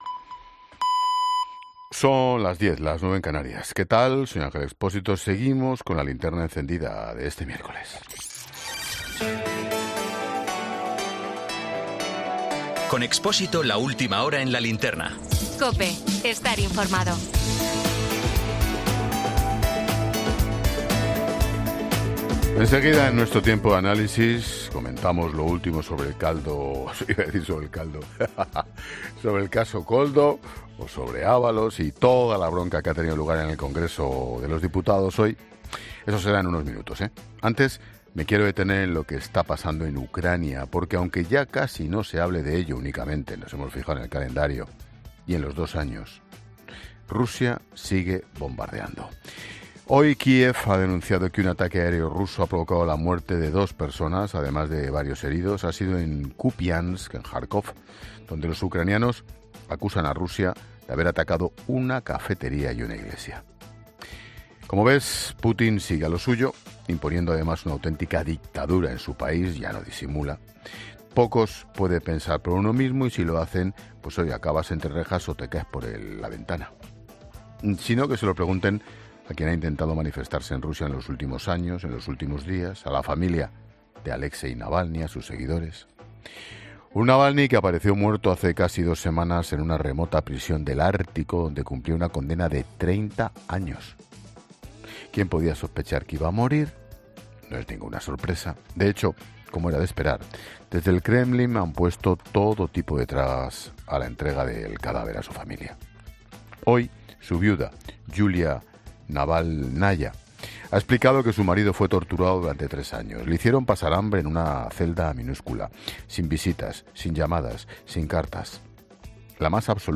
Boletín 22.00 horas del 28 de febrero de 2024 La Linterna